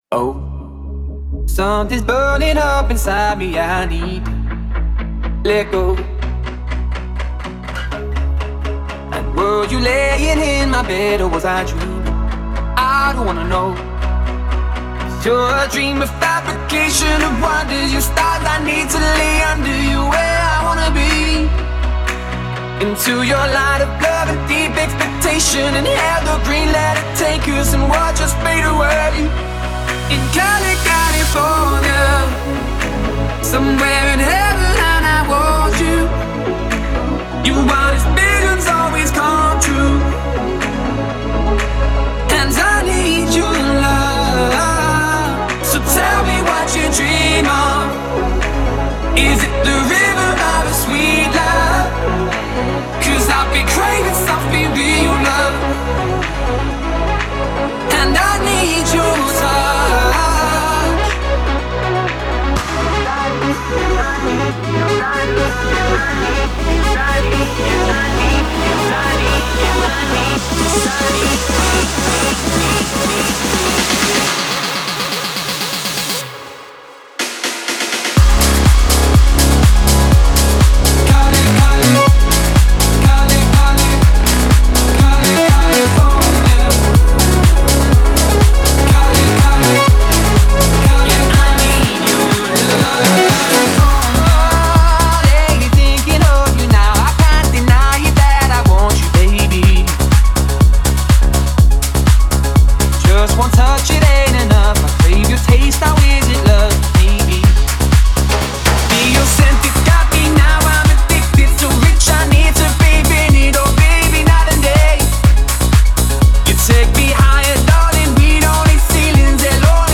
это зажигательная трек в жанре хаус